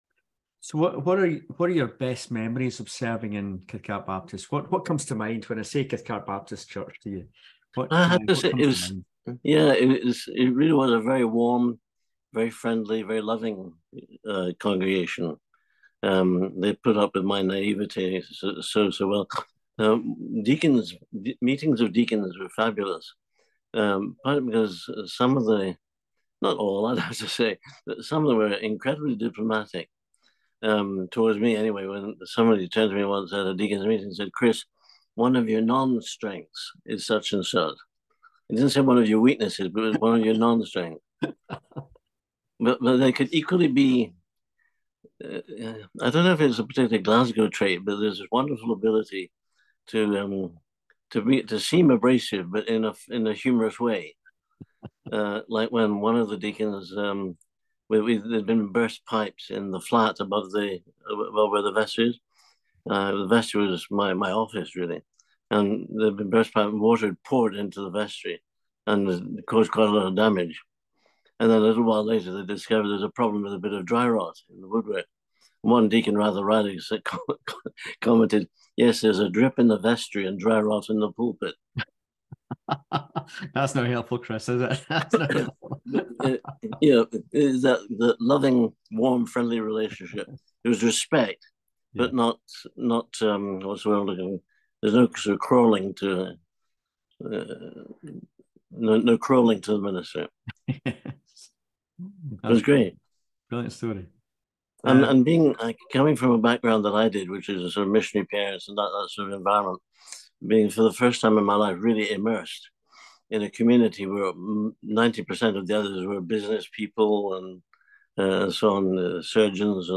recorded in 2023 over zoom.